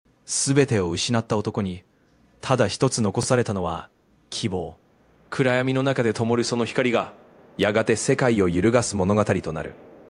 それはナレーション機能
AIとは思えないほどのクオリティー
使用ツールはchat GPTです。